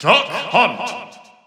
The announcer saying Duck Hunt's name in North American and Japanese releases of Super Smash Bros. 4 and Super Smash Bros. Ultimate.
Duck_Hunt_NA_Announcer_SSB4-SSBU.wav